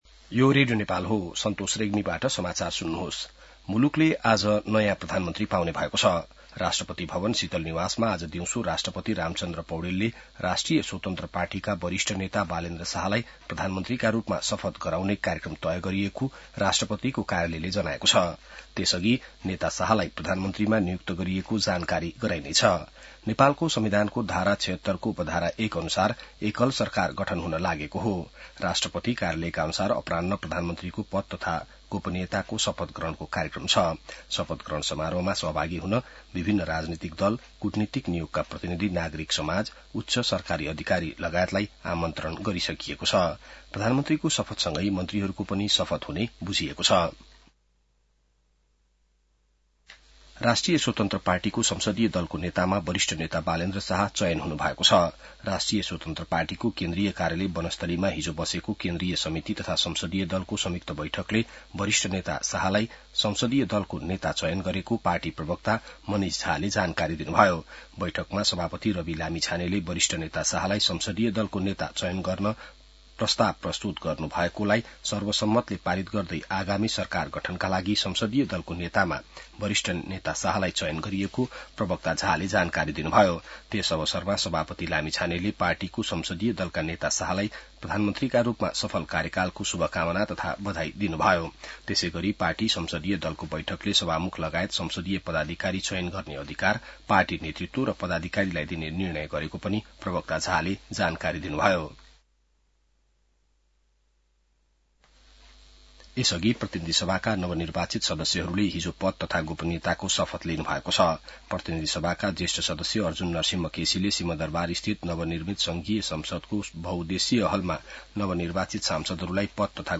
An online outlet of Nepal's national radio broadcaster
बिहान ६ बजेको नेपाली समाचार : १३ चैत , २०८२